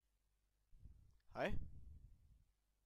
Ääntäminen
IPA: /haɪ/